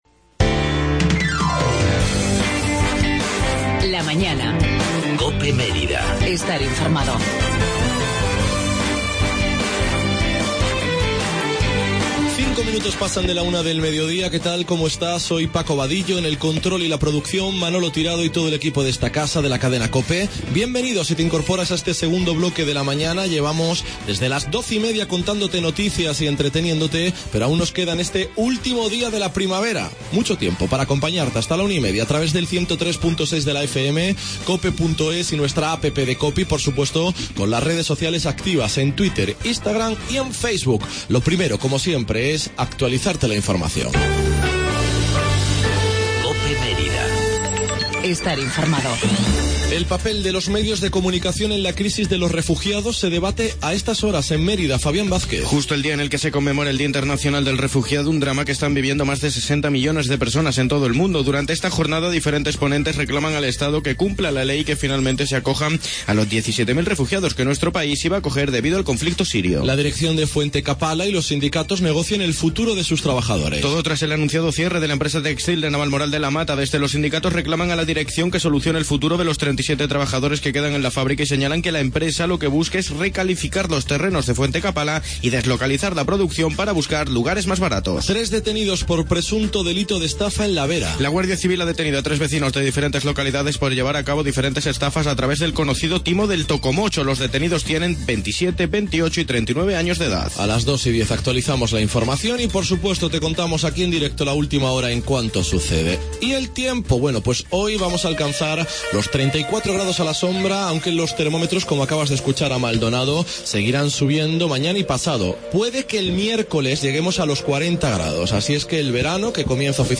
TERTULIA EUROCOPE EN LA MAÑANA DE COPE MÉRIDA 20-06-16